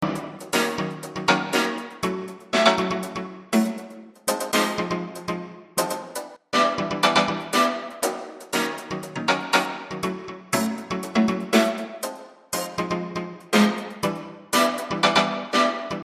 循环的关键是F。用70年代的Fender Jazz amp演奏，由FreeAmp3处理
Tag: 低音 BPM 电动